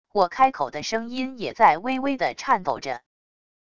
我开口的声音也在微微的颤抖着wav音频生成系统WAV Audio Player